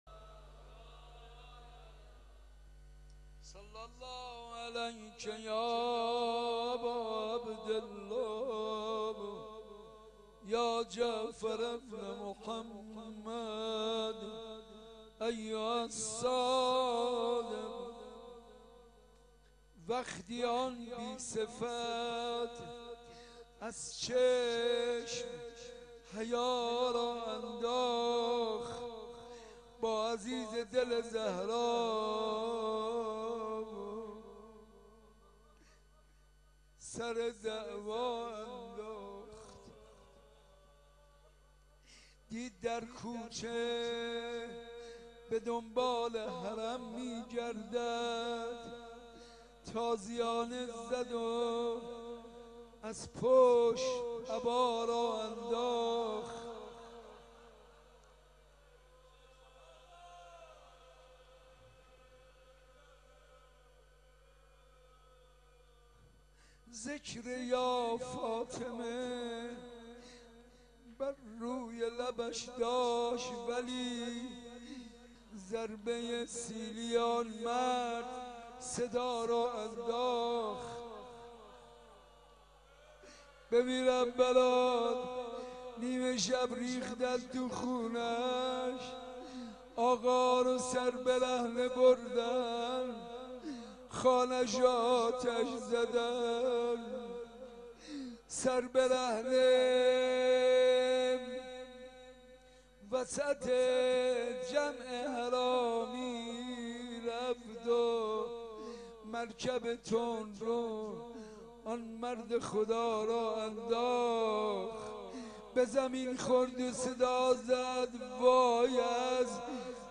روضه امام صادق علیه السلام